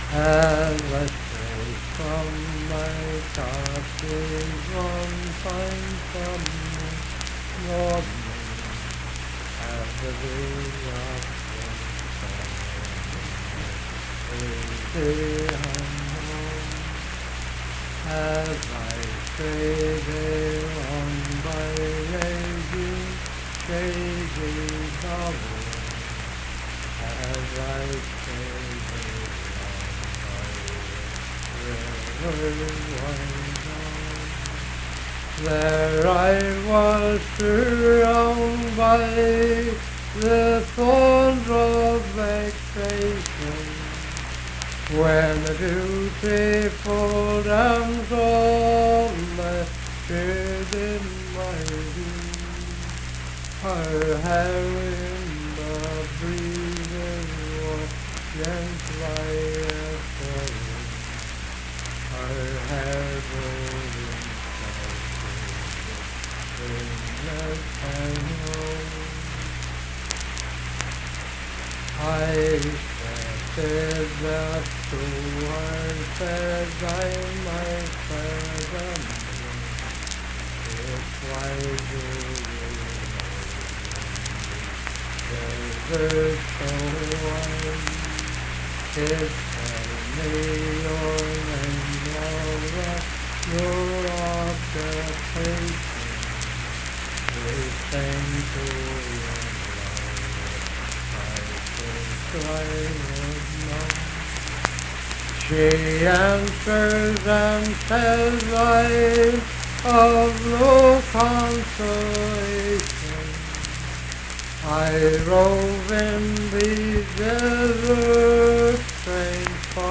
Unaccompanied vocal music
Richwood, Nicholas County, WV.
Verse-refrain 2(16).
Voice (sung)